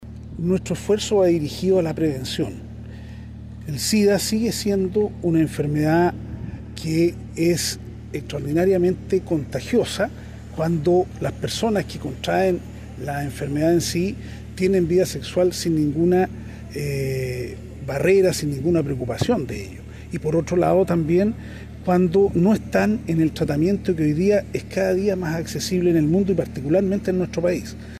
Eduardo Barra, seremi de Salud del Biobío, destacó la actividad como una forma de apoyar la prevención de enfermedades de transmisión sexual.